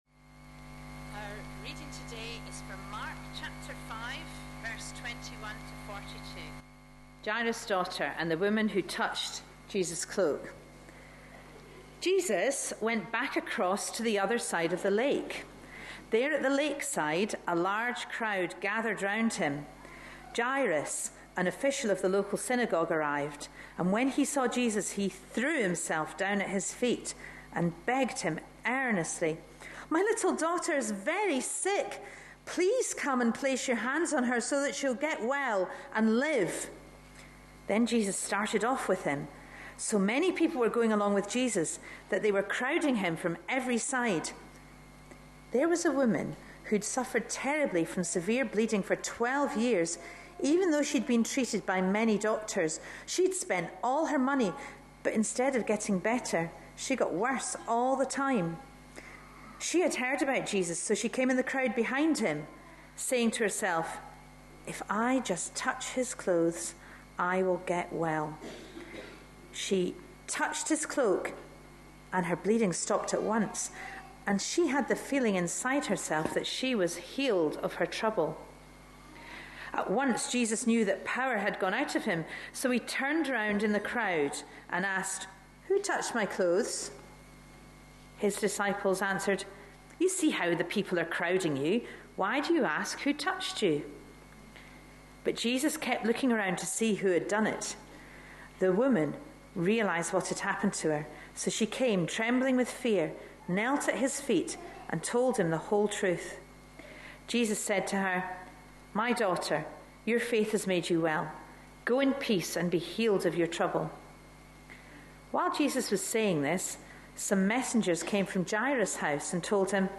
A sermon preached on 16th June, 2013.
This was an All-Age service for Father's Day; earlier, the service had included various "Dads' Challenges" -- tasks to be performed within 3 minutes -- and during the talk, children, fathers, and grandfathers were invited to collect sweets from a tin at the front.